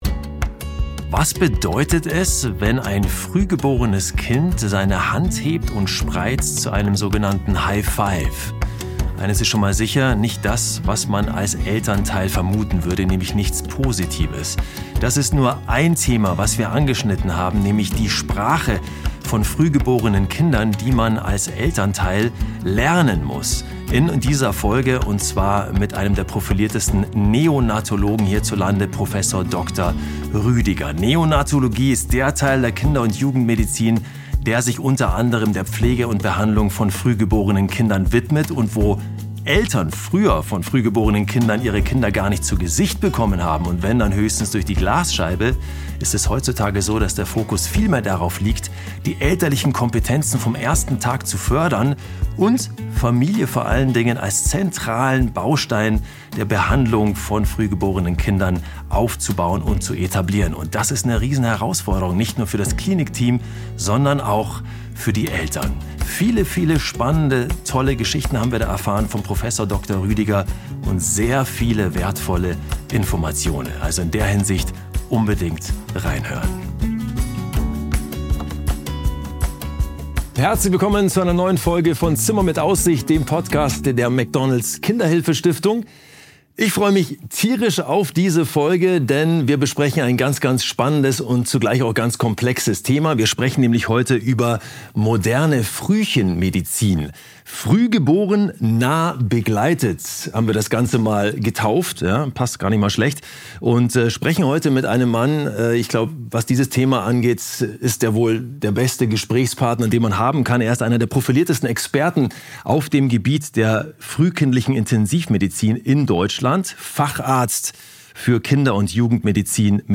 Ein Gespräch über Berührungsängste, Forschung, Familie und warum wir heute nicht nur das Überleben, sondern auch die gesunde Entwicklung von Frühgeborenen in den Blick nehmen müssen.